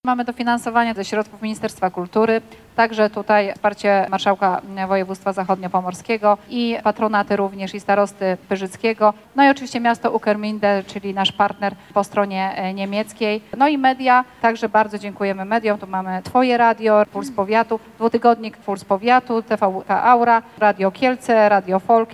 W dniu wczorajszym odbyła się konferencja prasowa organizatorów Międzynarodowego Festiwalu – 40 Pyrzyckie Spotkania z Folklorem.